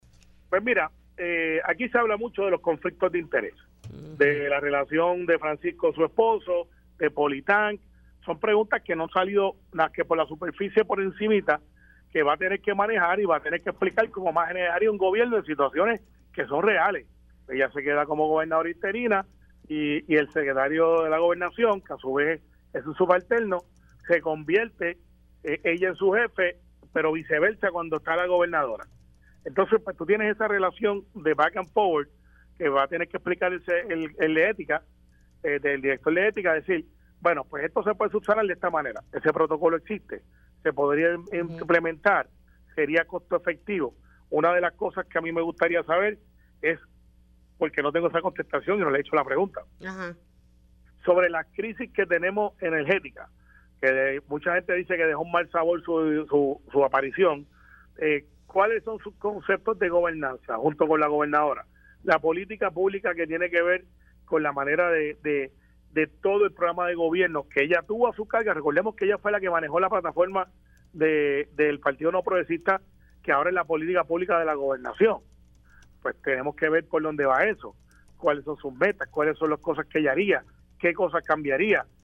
314-CARMELO-RIOS-SENADOR-PNP-QUIERE-SABER-SOBRE-POLITANK-CRISIS-ENERGETICA-.mp3